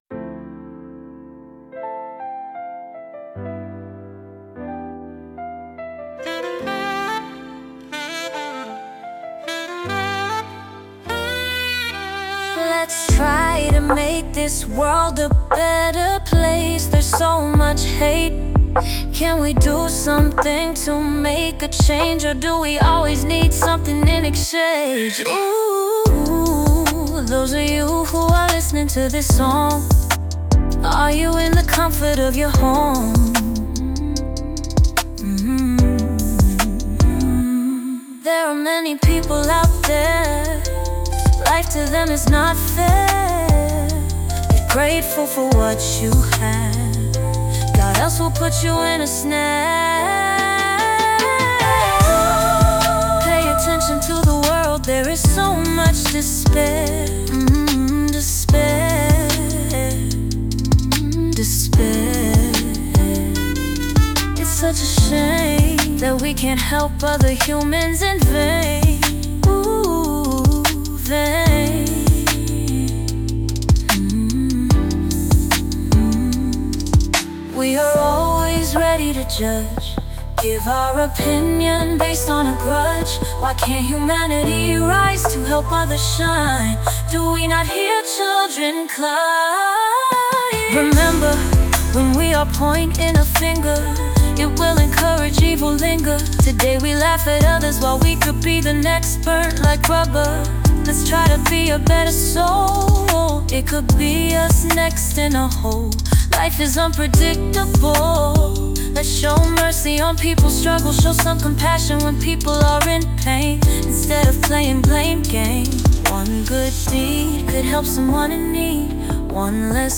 “Let’s Try To Make This World A Better Place” is a well-crafted, midtempo pop song that has a soulful groove to it. The song has a heartfelt message, about striving for peace and trying to make the world a better place.